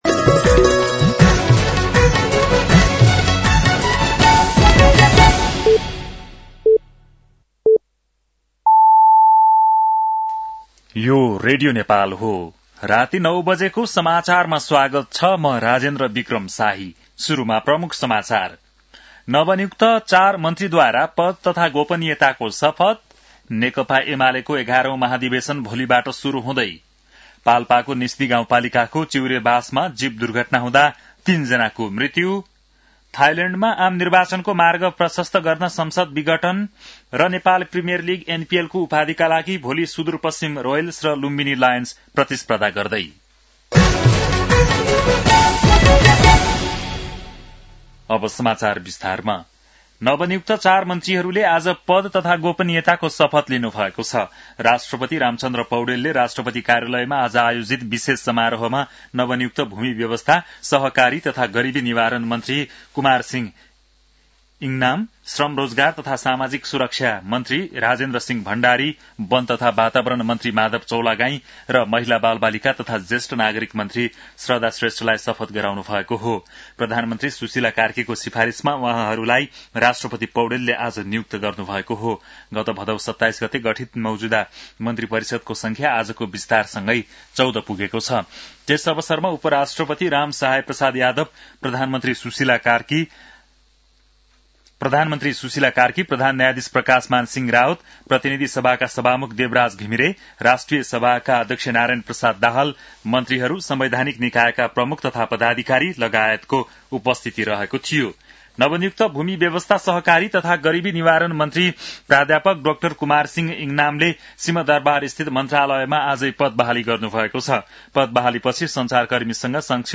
बेलुकी ९ बजेको नेपाली समाचार : २६ मंसिर , २०८२
9-pm-nepali-news-8-26.mp3